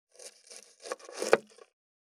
533切る,包丁,厨房,台所,野菜切る,咀嚼音,ナイフ,調理音,まな板の上,料理,
効果音厨房/台所/レストラン/kitchen食器食材